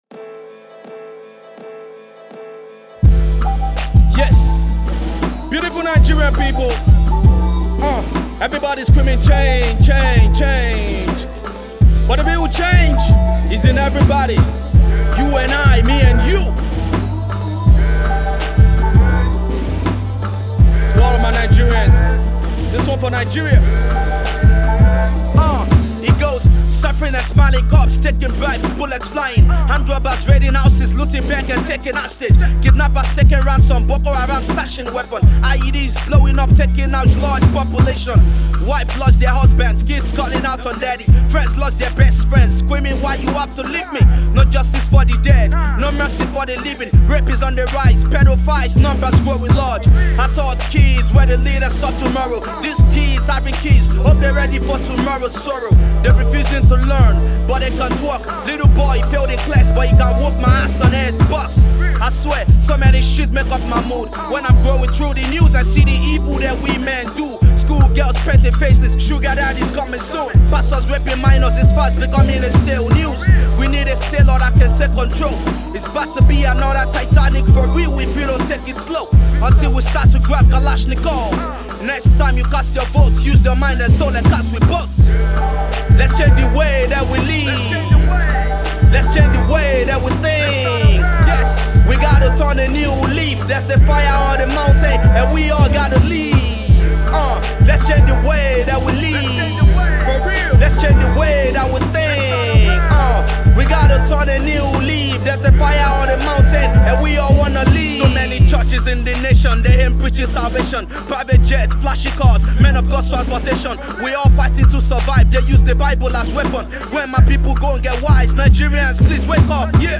Hip-Hop
sampled conscious tune